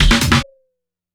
kick-snare02.wav